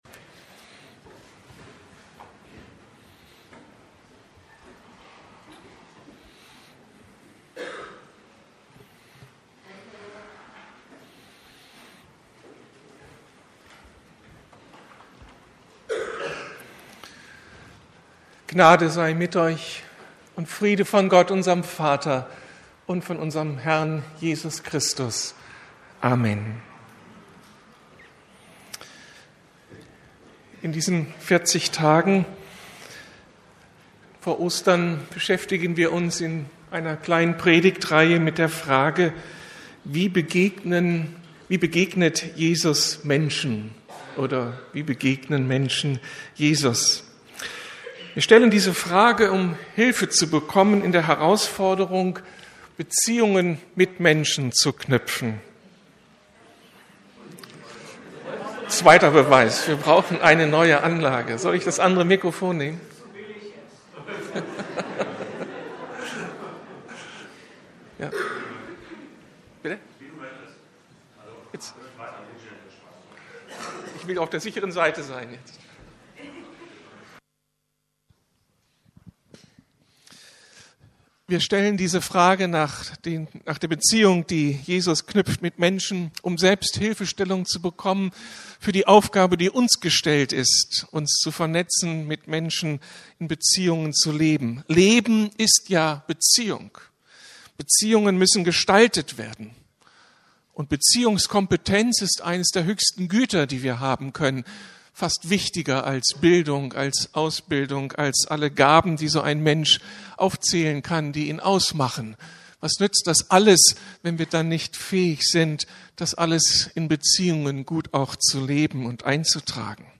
Begegnungen mit Jesus Eine wichtige Angelegenheit ~ Predigten der LUKAS GEMEINDE Podcast